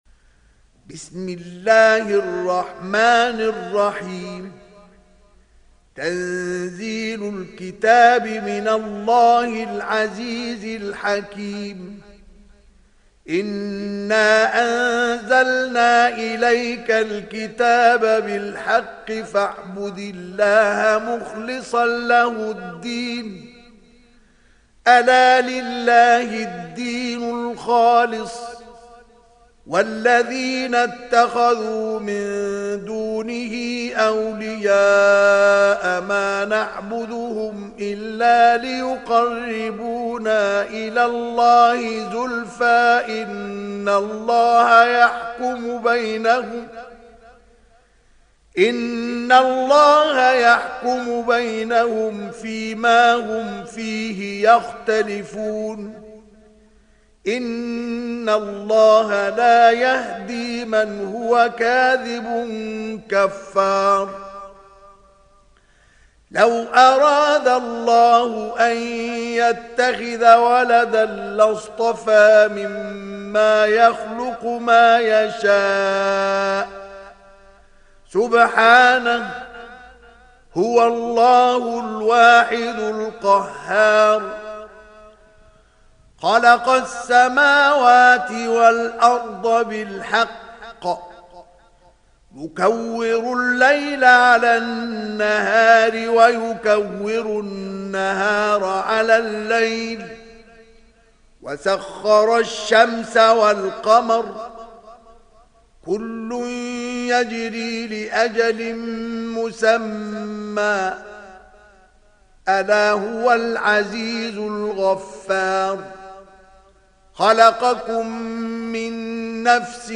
Surah Az zumar mp3 Download Mustafa Ismail (Riwayat Hafs)